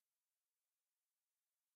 Shoot_1.mp3